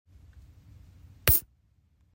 Пенни упалм на кровать
penni_upalm_na_krovat_4ps.mp3